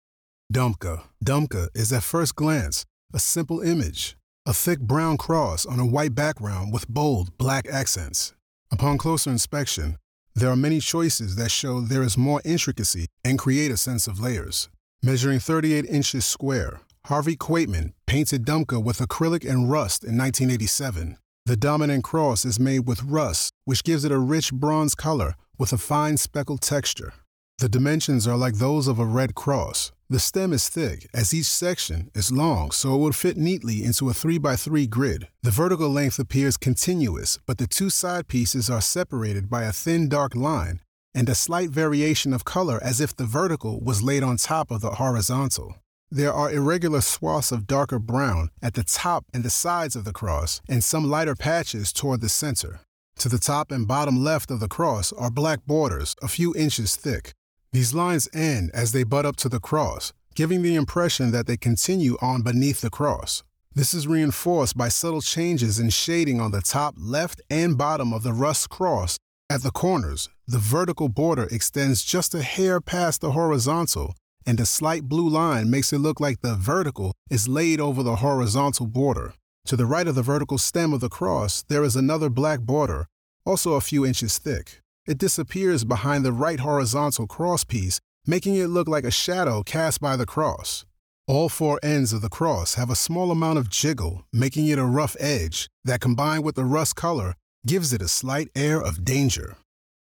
Audio Description (01:40)